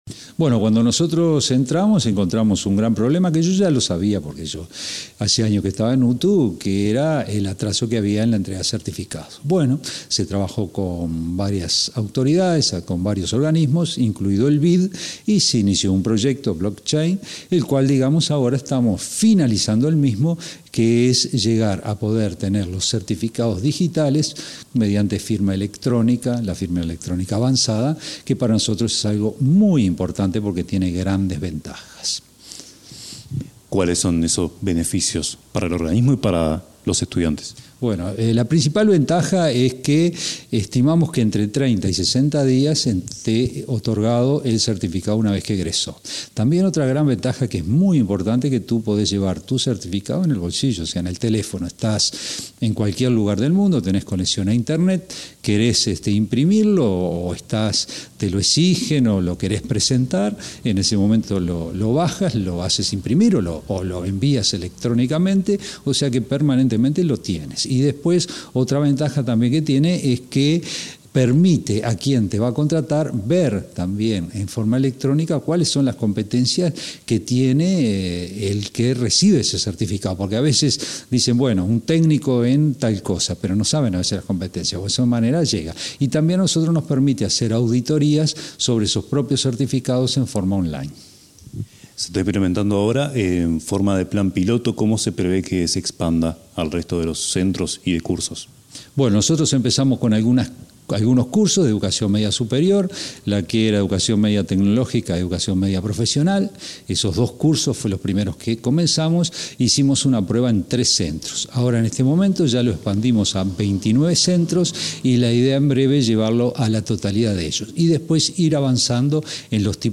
Entrevista al director general de Educación Técnico Profesional, Juan Pereyra